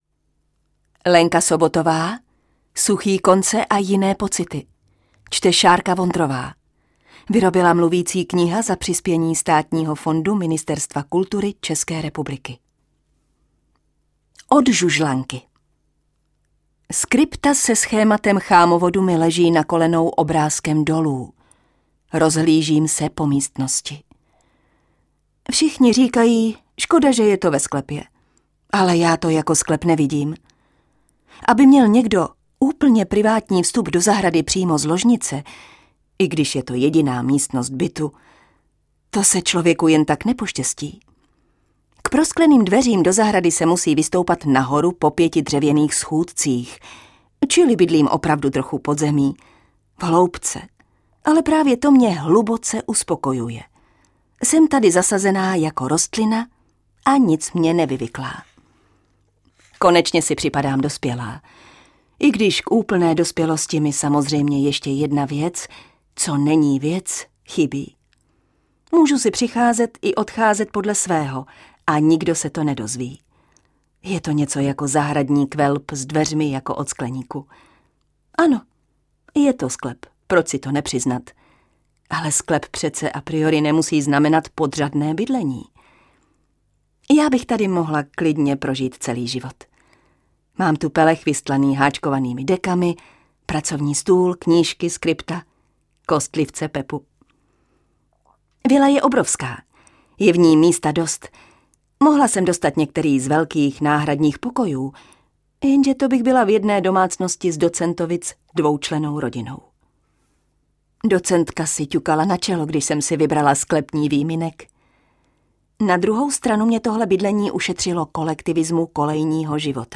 Čte: